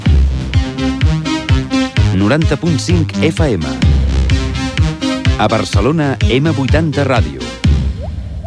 ecb3dbb78e4c82ee74a66f83c38437e82dca31cf.mp3 Títol M80 Barcelona Emissora M80 Barcelona Cadena M80 Titularitat Privada estatal Descripció Identificació de l'emissora a Barcelona, als 90.5 MHz.